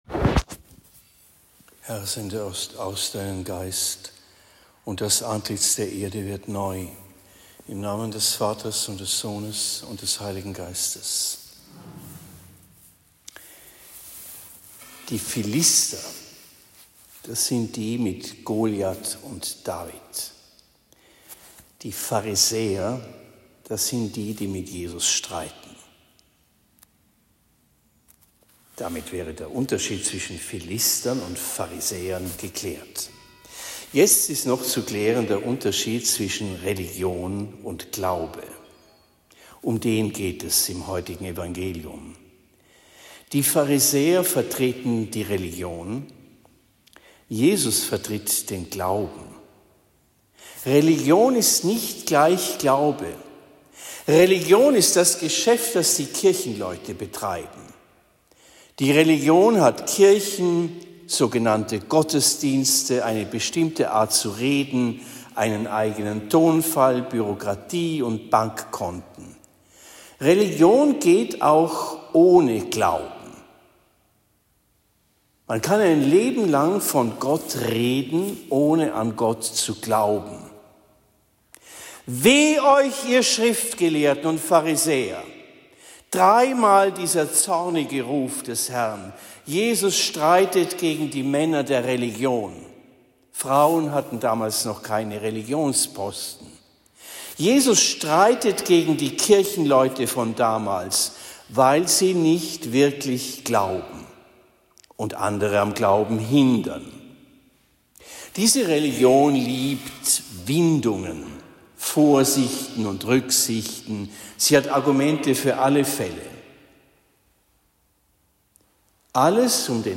Predigt in Bischbrunn am 26. August 2024